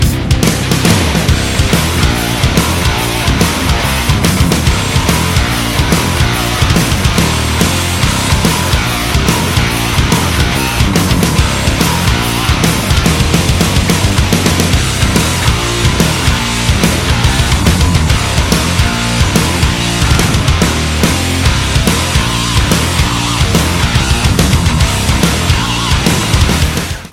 Отличный Хеви Метал. Потрясающий по силе проигрыш.